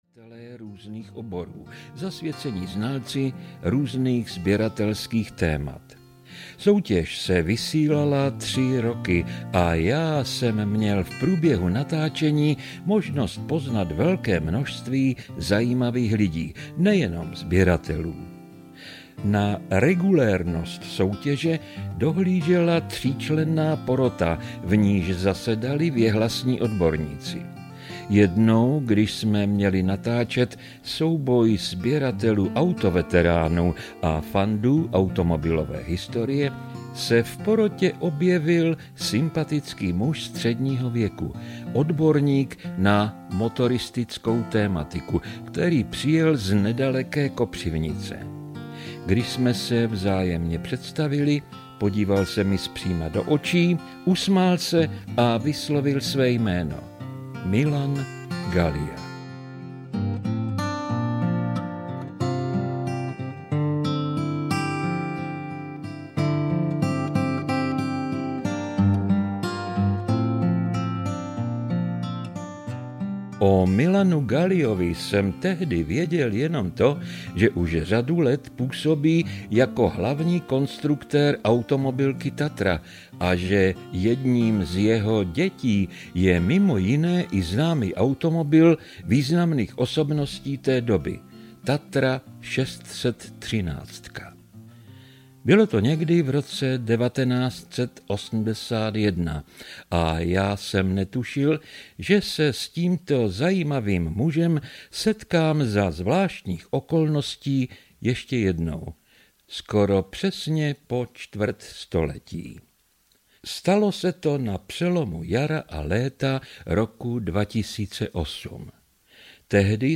Život s erbem Tatry audiokniha
Ukázka z knihy